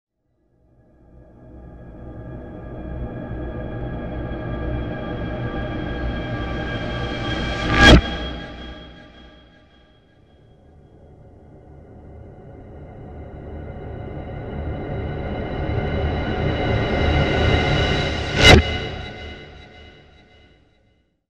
creepy-sound